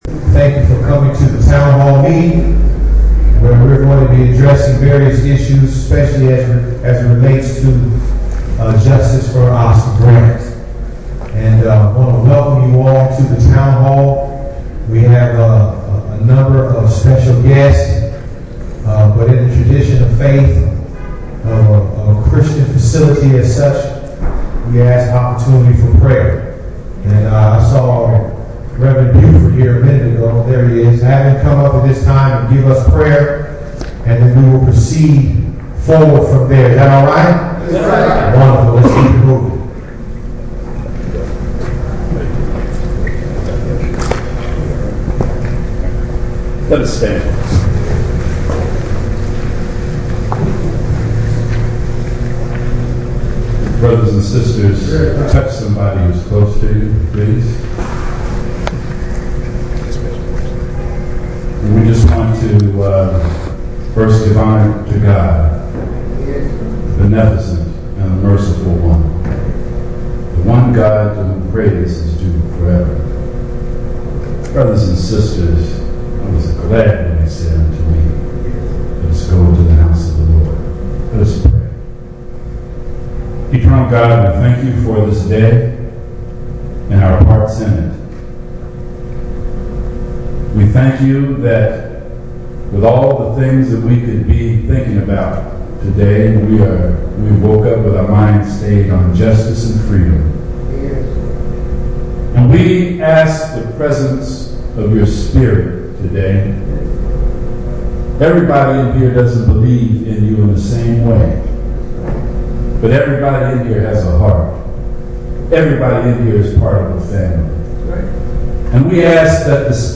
Town Hall Covers Mehserle Case and Recall of Orloff, Oakland, 3/21/09: photos and audio
leads attendees in prayer.